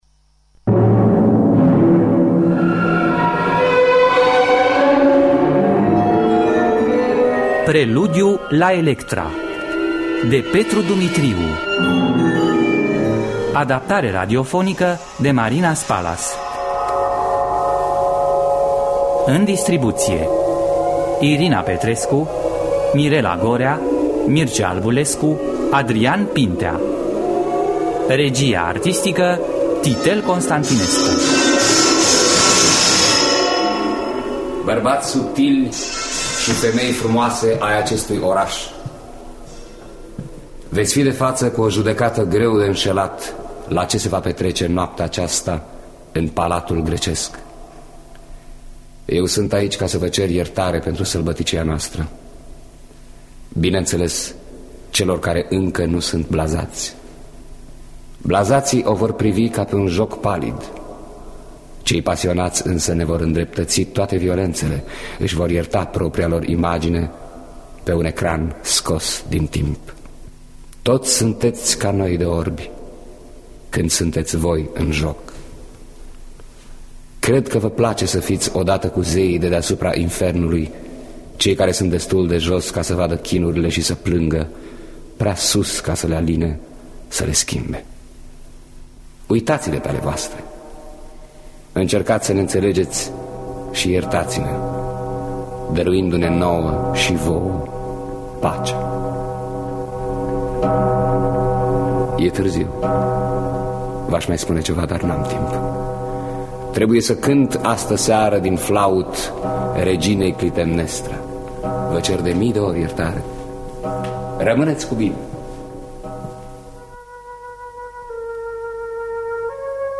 Adaptarea radiofonică de Marina Spalas.